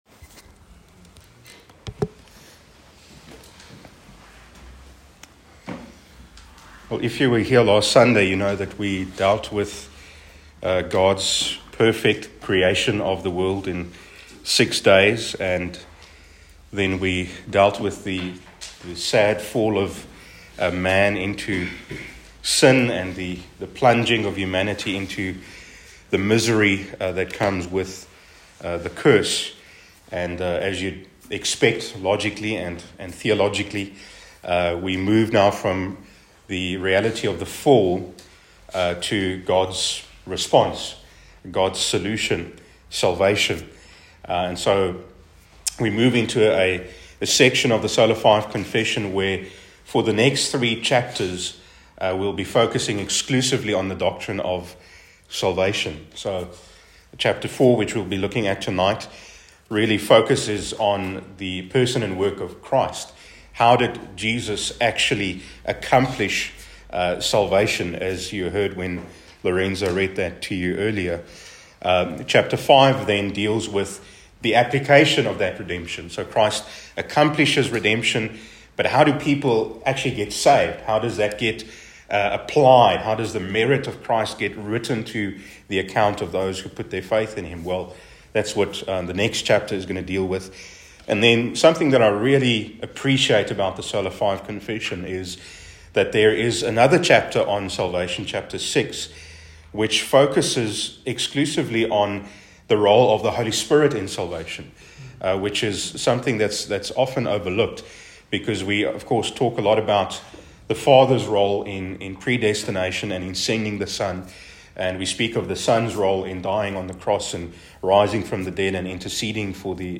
A message from the series "Sola 5 series."